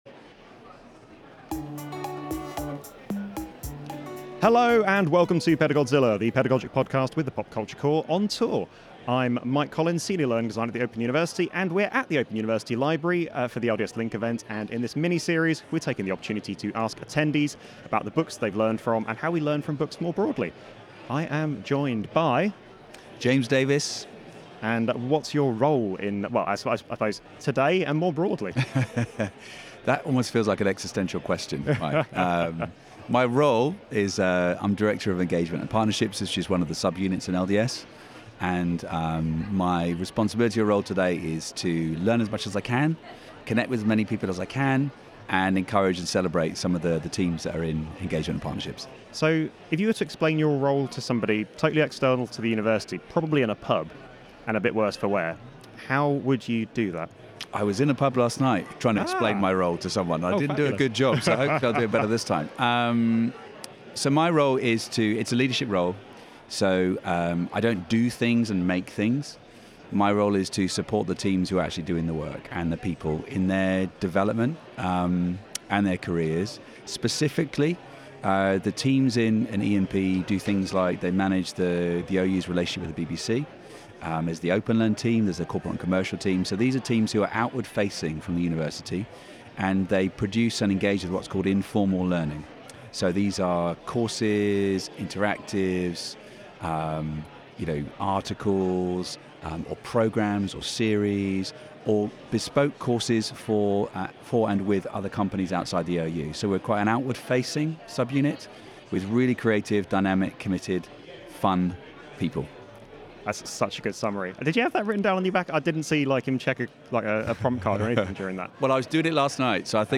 We set up our cosy little podcast booth at the Open University library for the LDS Link event – replete with a health and safety nightmare of t…